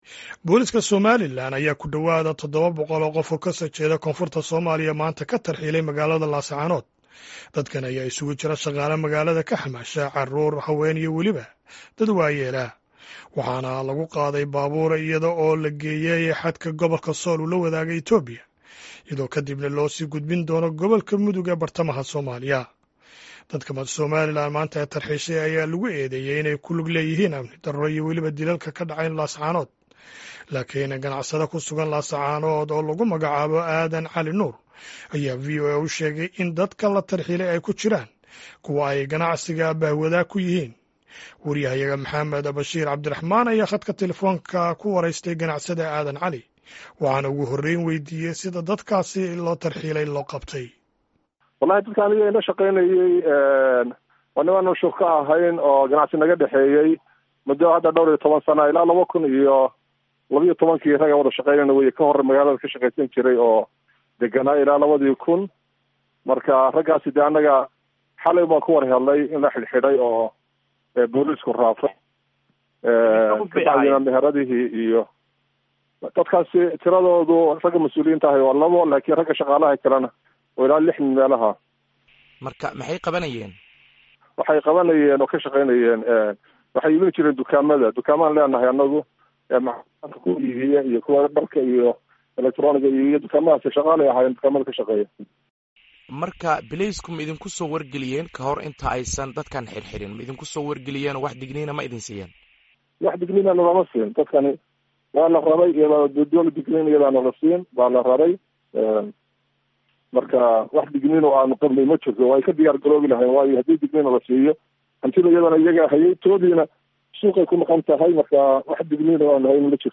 Wareysi aan la yeelanay ganacsade ku sugan Laascaanood